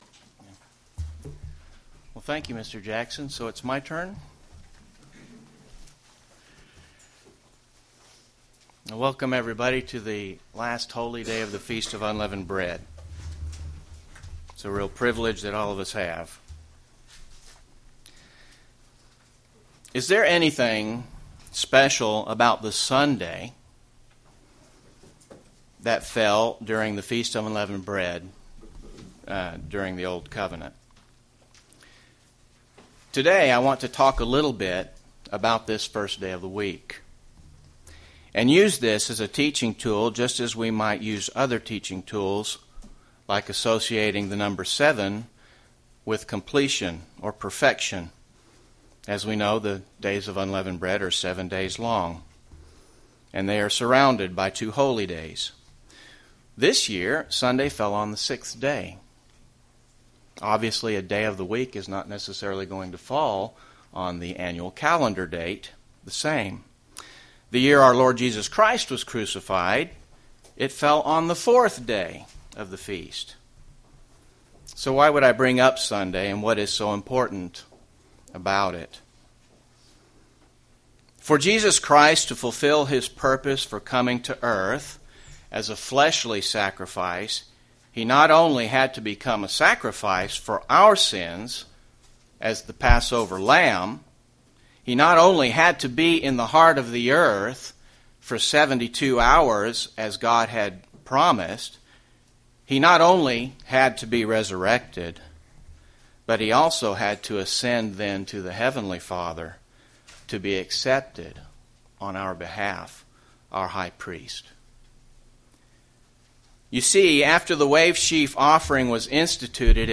UCG Sermon Jesus Christ Jesus Christ resurrection Eternal Life hope for all mankind Notes Presenter's Notes Is there anything special about the Sunday that falls during the Feast of Unleavened Bread?